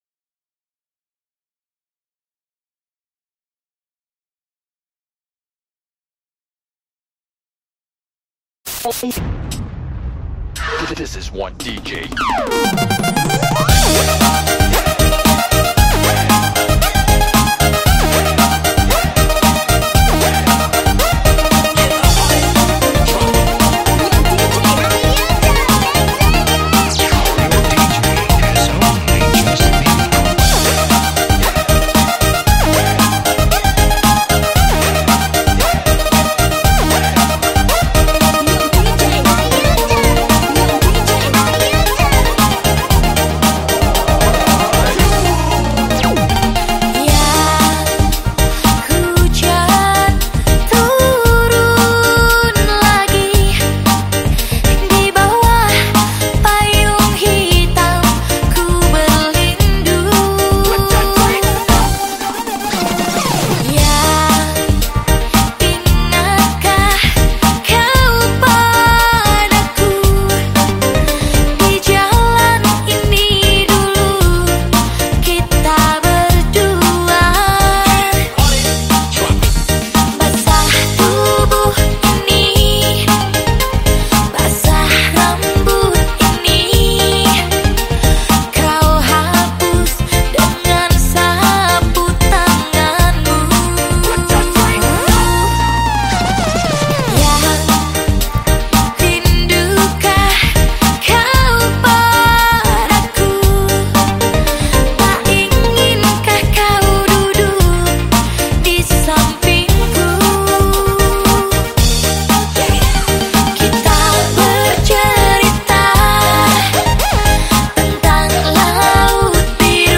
terbaik dari dangdut koplo.